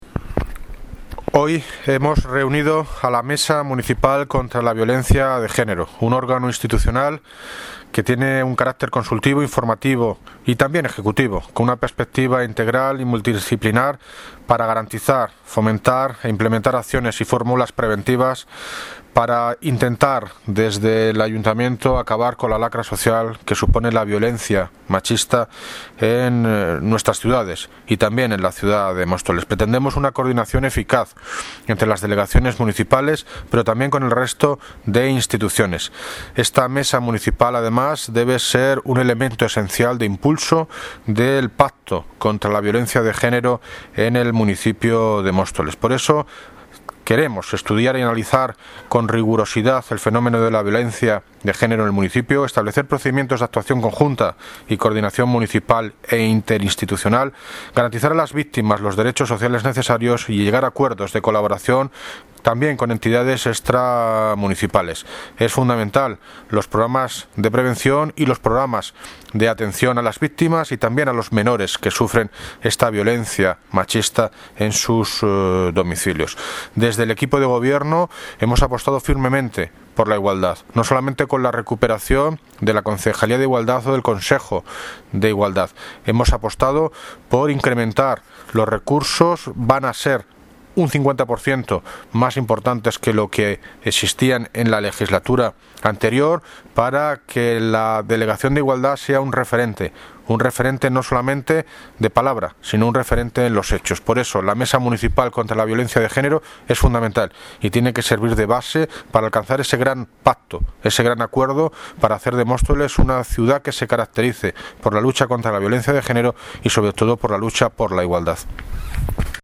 Audio - David Lucas (Alcalde de Móstoles) sobre mesa violencia de género